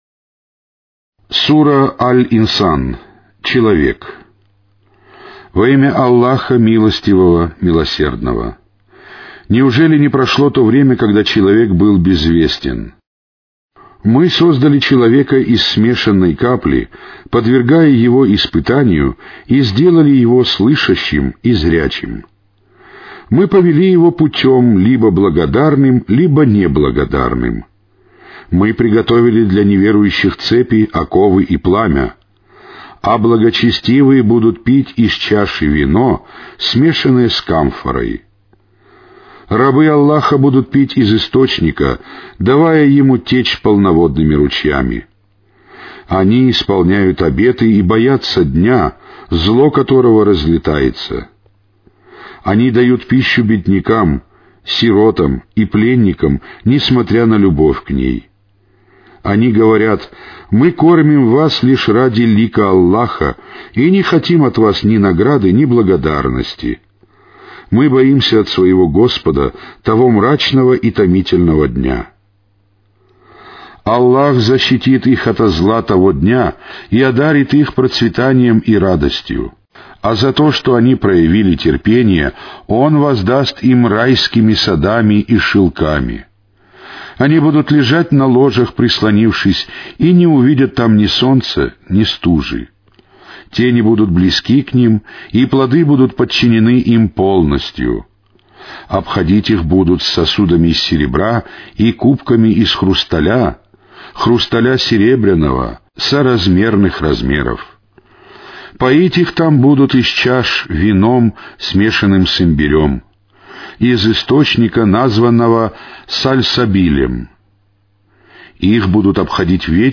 Качество звука 24кбит/сек. Каналы 1 (Mono). Частота дискретизации 11025hz. Формат звука (mp3) - MPEG 2.5 layer 3
Аудиокнига: Священный Коран